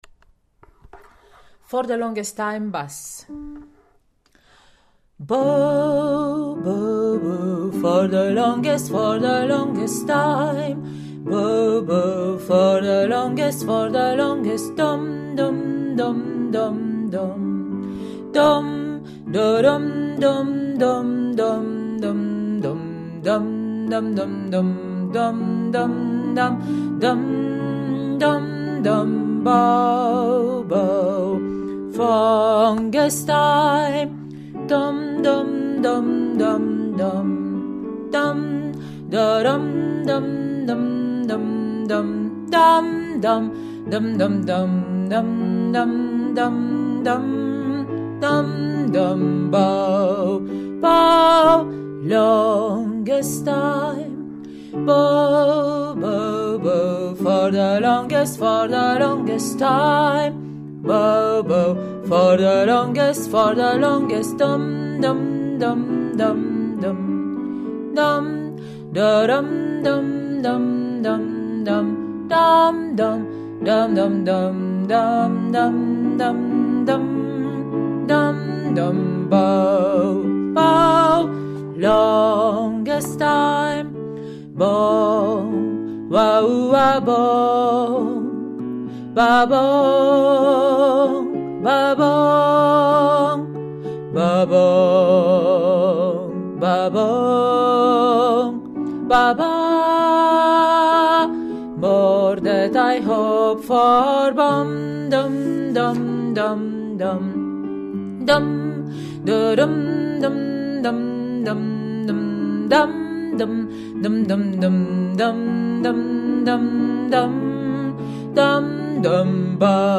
For the longest time – Bass